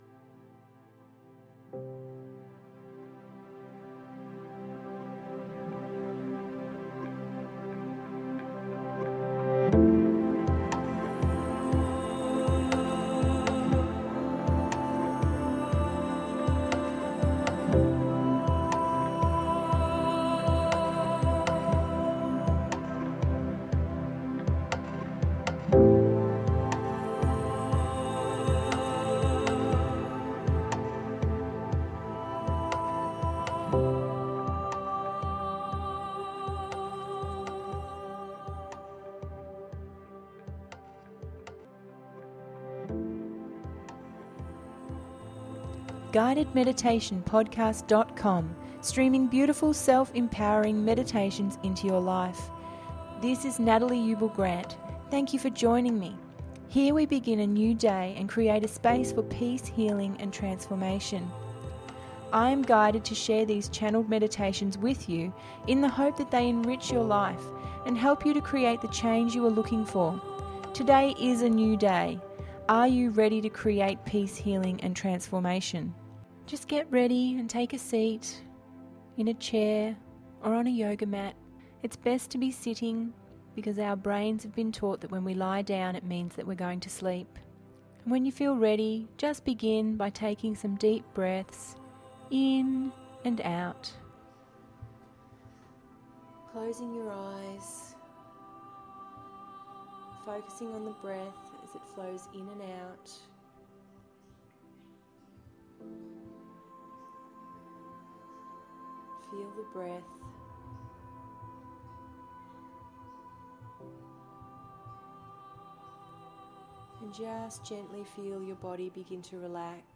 So today we have a great little meditation that will assist you to return all of your energy back to you.
Be guided to ground your energy and cut away the cords that no longer serve you.